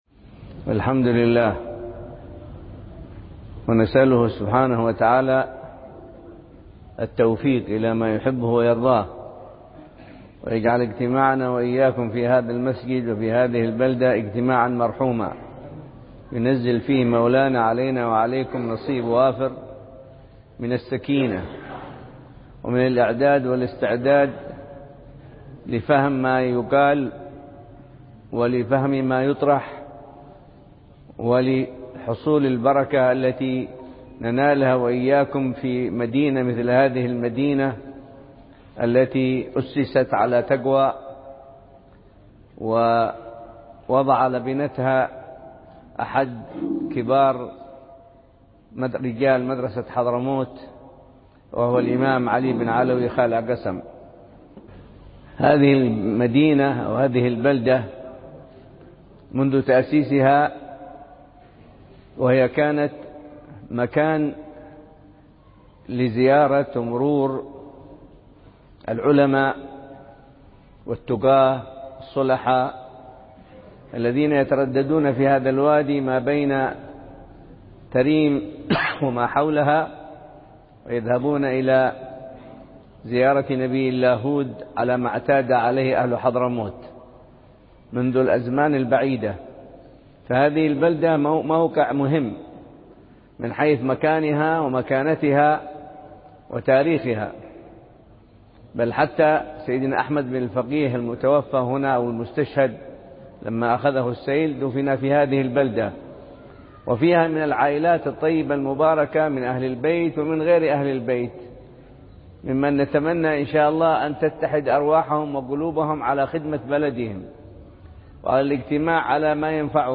محاضرة
بجامع منطقة قسم – شرقي مدينة تريم .. ضمن فعاليات الأسبوع الثقافي الأول الذي ينظمه منتدى قسم التنموي.